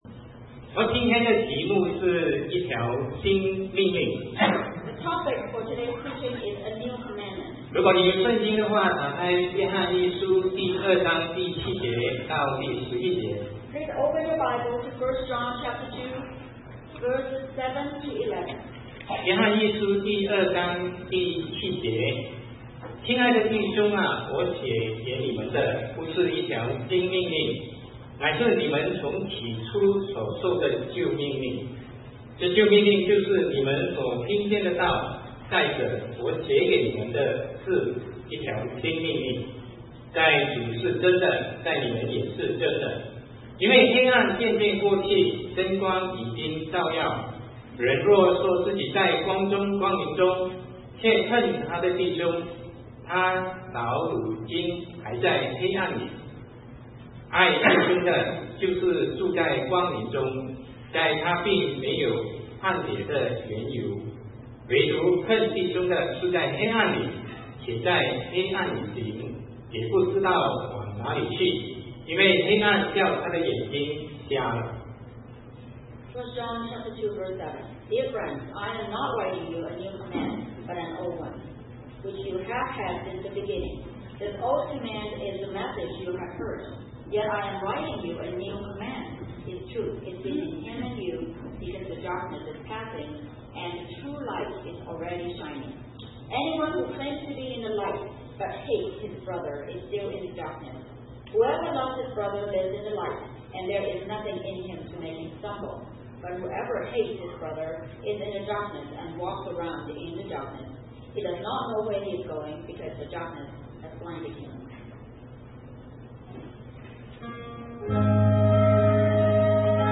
Sermon 2009-09-06 A New Commandment